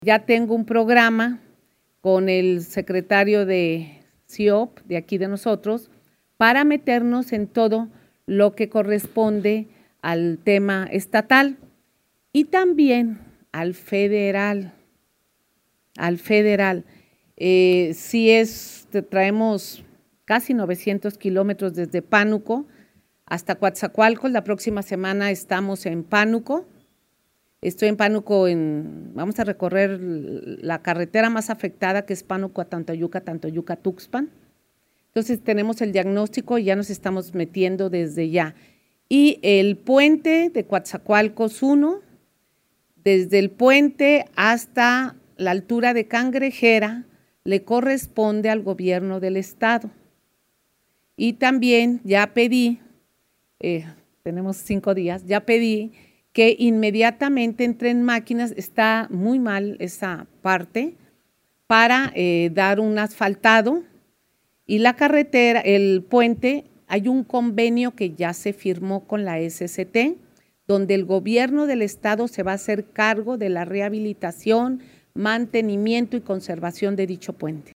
En un ejercicio de rendición de cuentas ante los medios de comunicación y la población, la titular del Poder Ejecutivo dio a conocer la agenda de intervención a la red carretera en coordinación con la Secretaría de Infraestructura, Comunicaciones y Transportes.